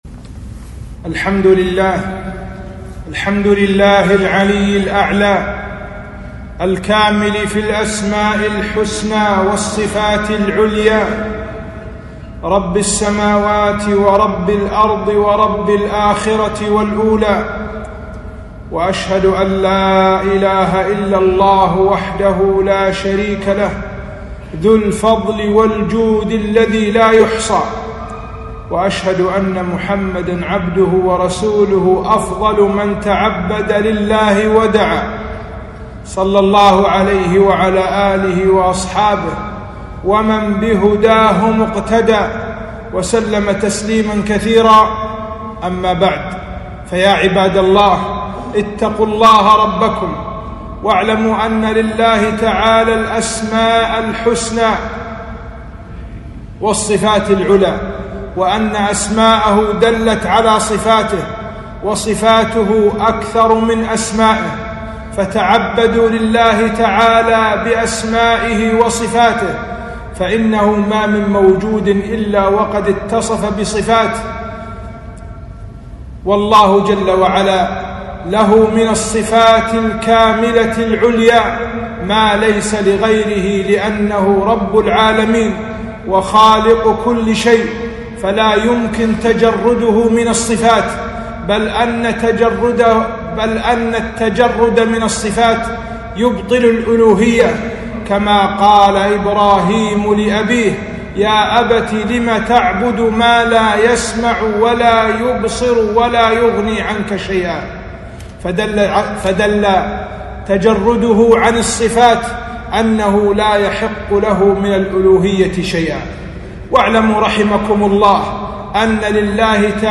خطبة - التعبد لله بأسمائه وصفاته